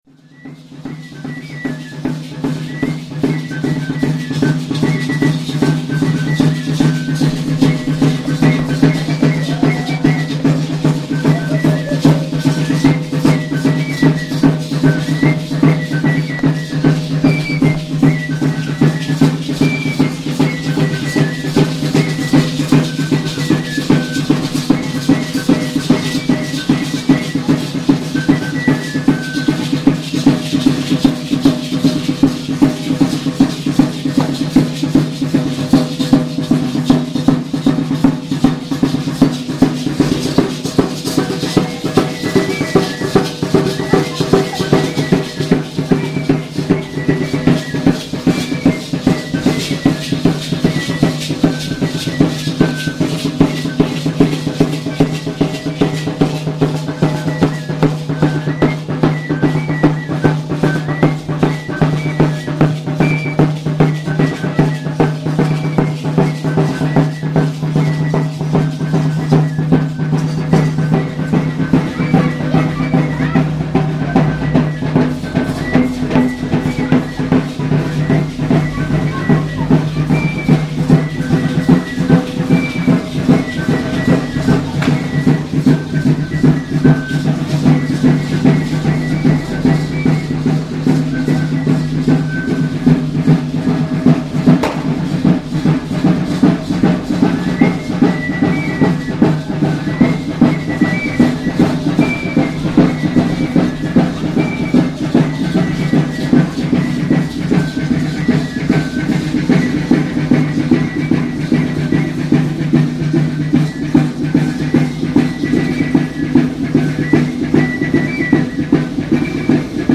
Cada vez es más común observar que en esas procesiones son encabezadas por un pequeño grupo de parachicos que danzan al ritmo del tambor y pito.
El siguiente audio es un pequeño fragmento de la salida de un grupo de personas con una enrama a la virgen de Santa Cecilia, conocida como la patrona de los músicos y celebrada el 22 de noviembre.